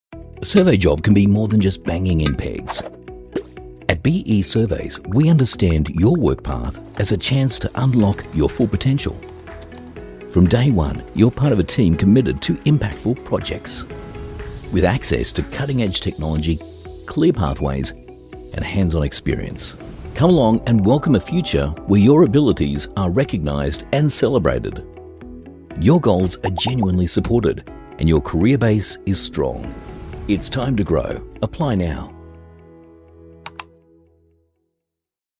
Doubleur anglais (australien)
Narration